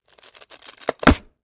Звуки пенопласта
Пенопласт - Альтернативный вариант 3